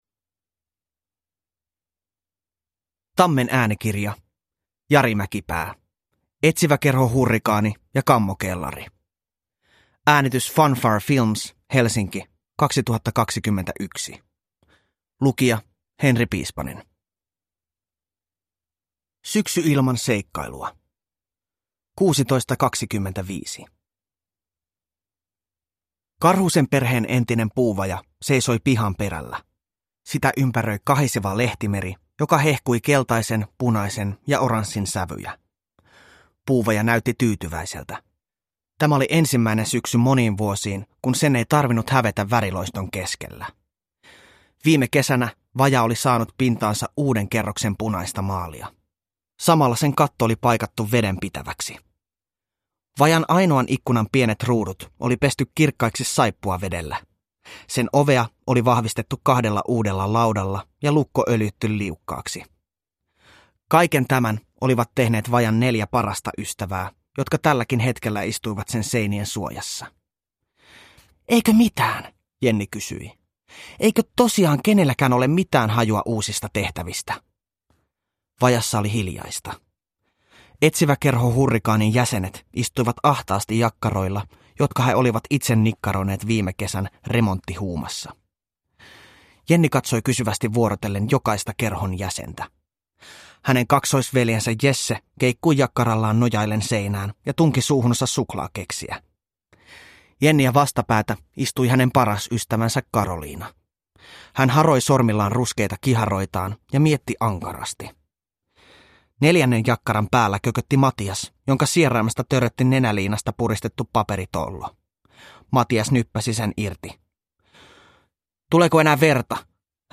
Etsiväkerho Hurrikaani ja kammokellari – Ljudbok – Laddas ner